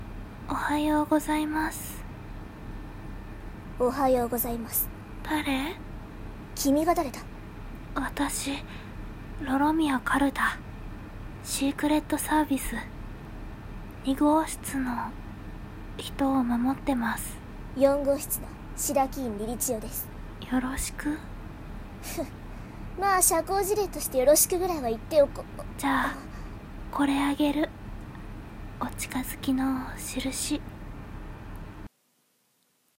妖狐×僕SS 白鬼院凛々蝶(CV:日高里菜) 髏々宮カルタ(CV:花澤香菜) 声真似